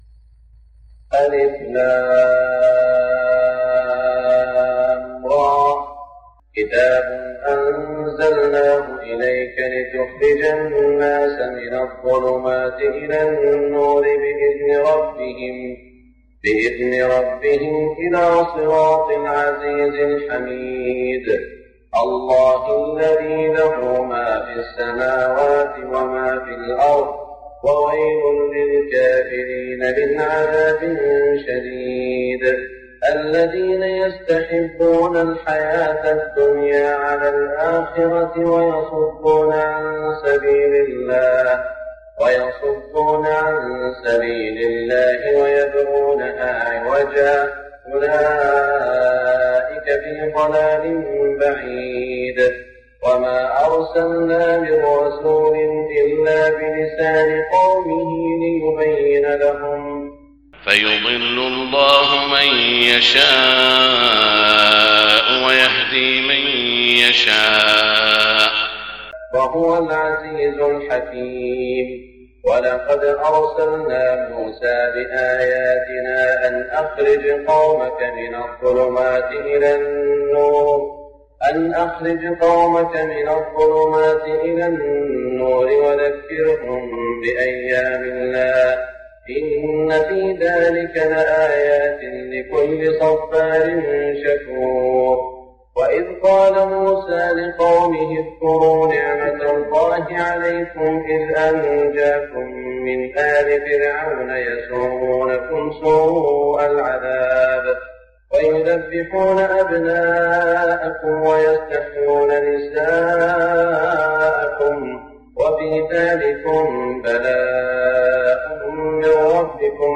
صلاة الفجر ١٤٢٤ فواتح سورة إبراهيم > 1424 🕋 > الفروض - تلاوات الحرمين